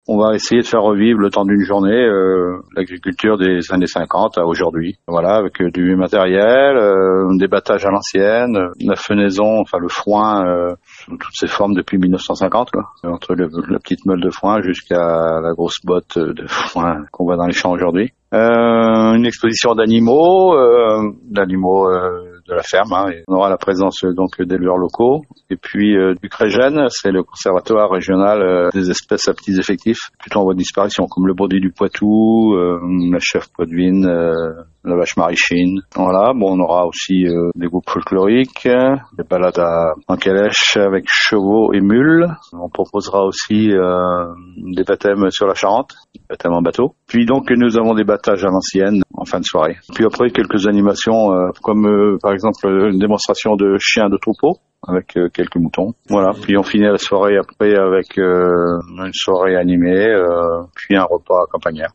Au programme : exposition d’animaux de la ferme, des métiers d’autrefois, démonstrations de danses traditionnelles charentaises, et à 11h un défilé d’animaux et de tracteurs anciens dans le centre-ville. Jean-Philippe Guignouard, conseiller municipal délégué à la ruralité, nous en parle :